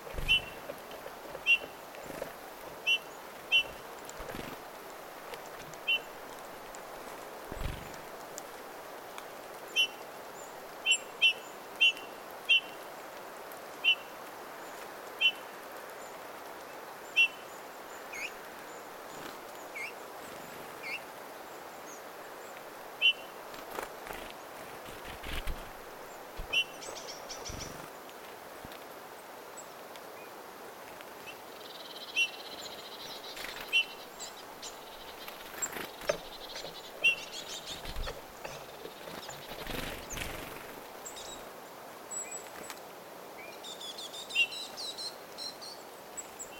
tuollaista värisevää punatulkun ääntelyä
tuollaista_varisevaa_punatulkkulinnun_aantelya.mp3